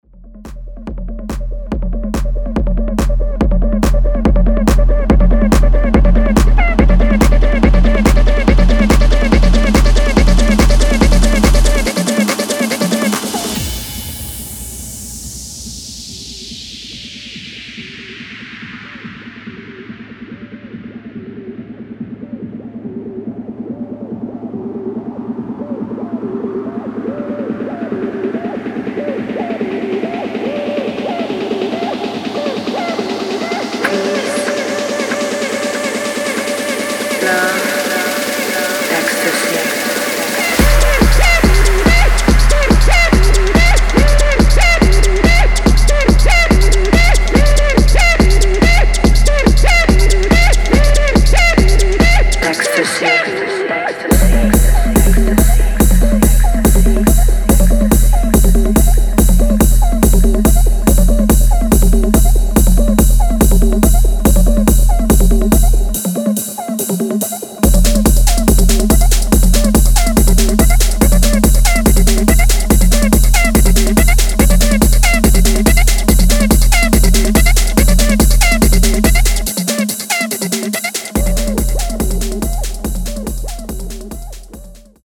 Electronic
Techno, Hard Techno, Hardstyle, Jumpstyle